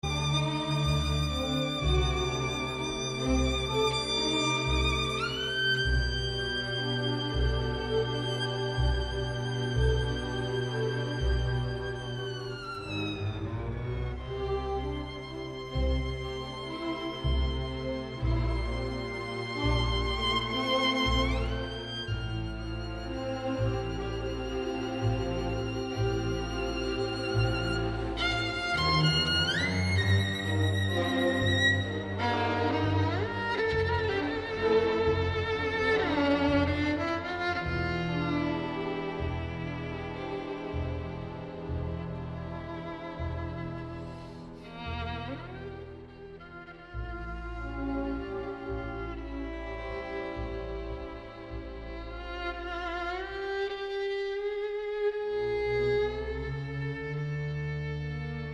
小提琴